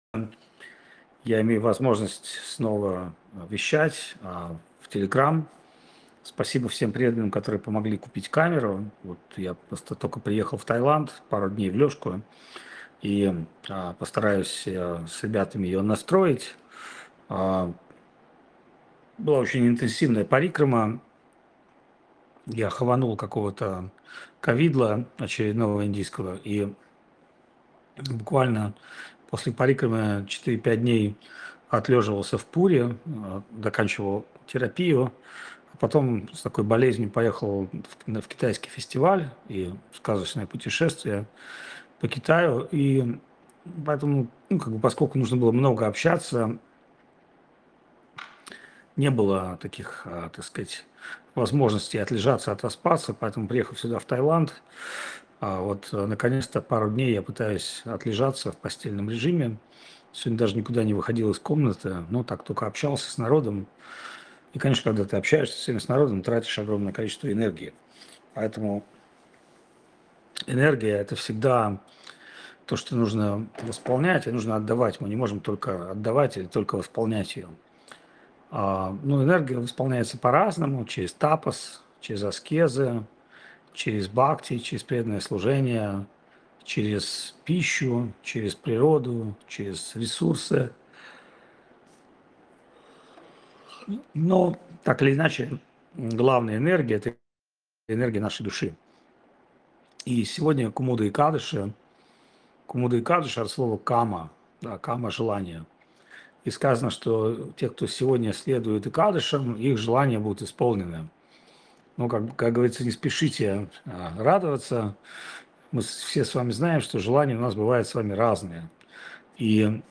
Чиангмай, Таиланд
Лекции полностью